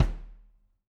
Index of /musicradar/Kicks/Loose Kick
CYCdh_LooseKick-05.wav